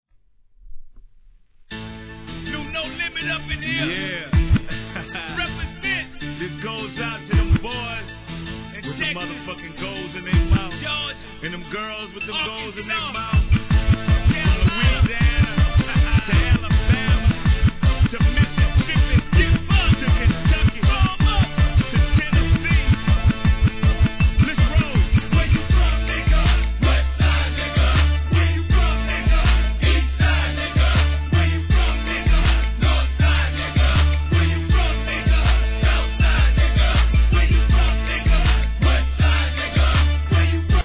G-RAP/WEST COAST/SOUTH
スクラッチ＆印象的なシンセ・メロディ〜から分かりやすいフックでテンション上げます!!